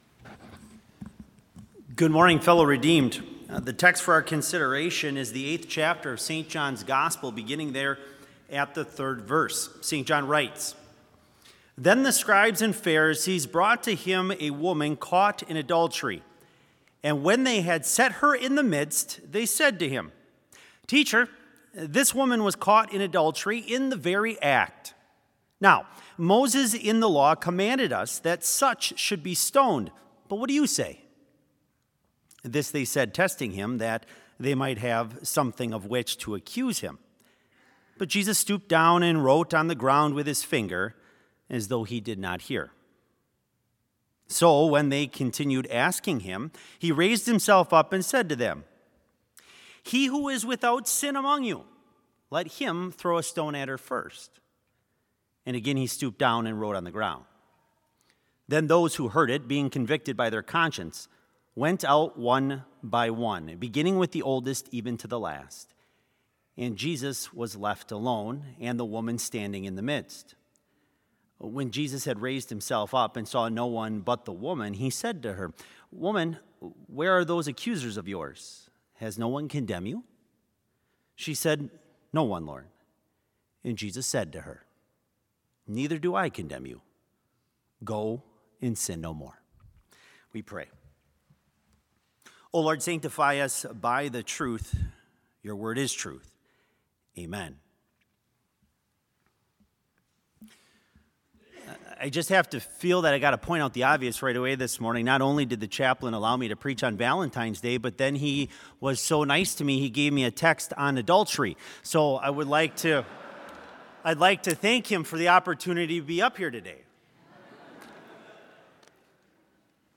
Complete service audio for Chapel - February 14, 2020